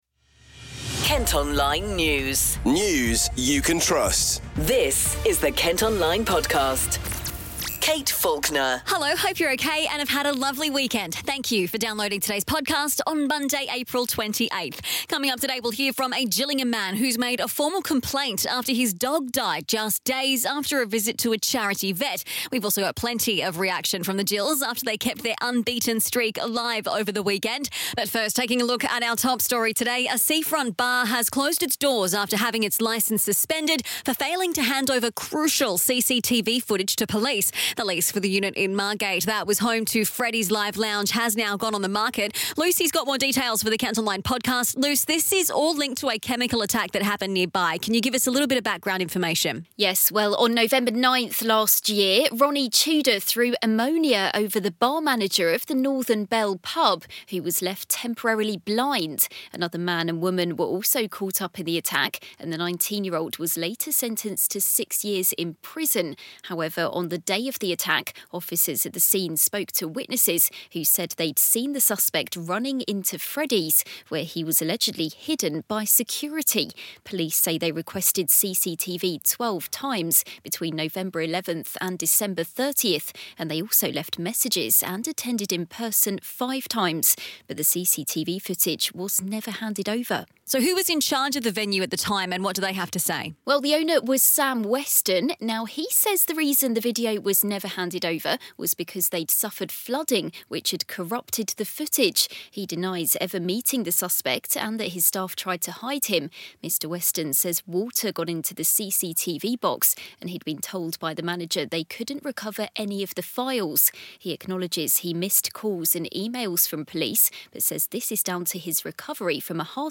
More than 900 people had confirmed their attendance on Facebook for an event titled: ‘Stop the boats now! 10,000+ bikers and friends illegal migrant protest ride to Dover’ – you can hear from some of the demonstrators.